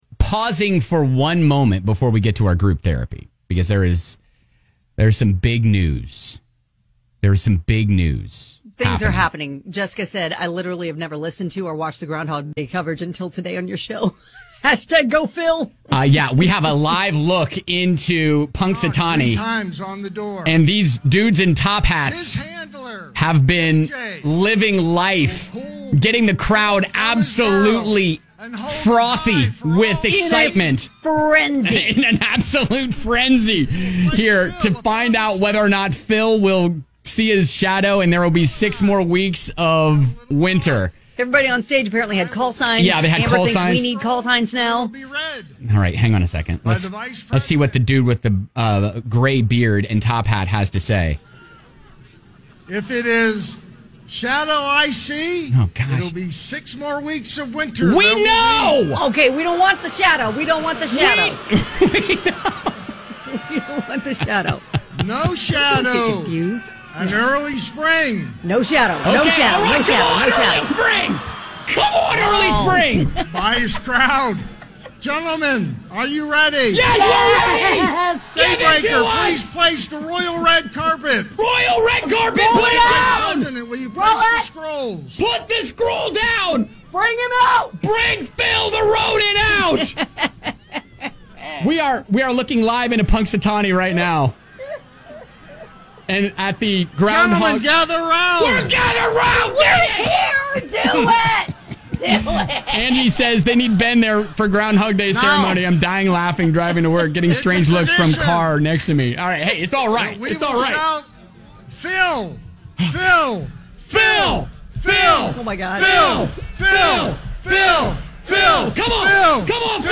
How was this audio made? We had to stop everything to bring you the live report of Phil the Groundhog!